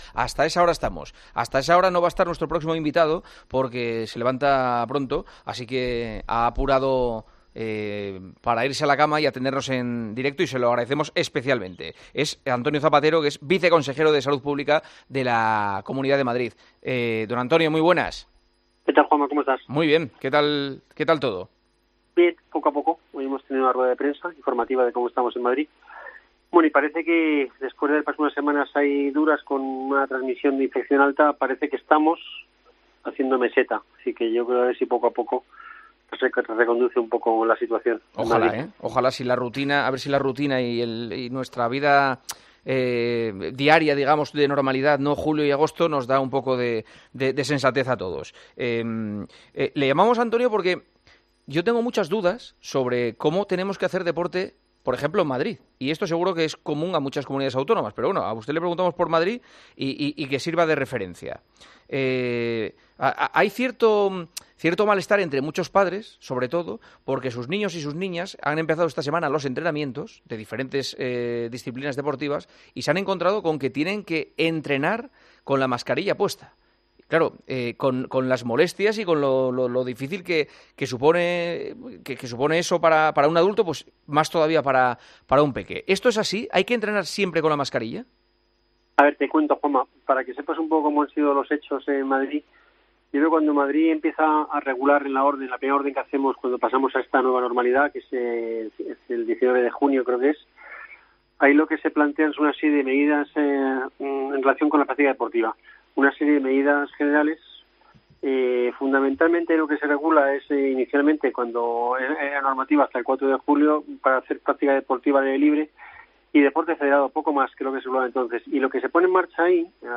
AUDIO: Hablamos con el viceconsejero de Salud Pública de la Comunidad de Madrid sobre las medidas obligatorias para realizar deporte.